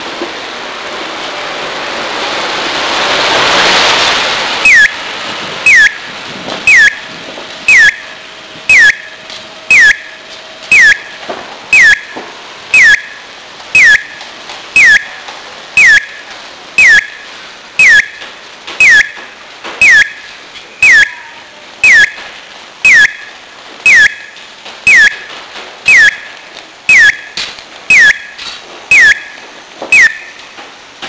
また、未編集なために音が悪かったりしますがそちらもご了承ください。
続いて2ファイル目は広島県の尾道駅近くで見つけた京三製の警交仕規第21号のピヨです。
西日本ではよく聞くタイプのピヨだと思いますがなんか妙に音程が低いです。
また、音量がものすごく大きく、スピーカーの位置もだいぶ低かったのでかなり録りやすかったです。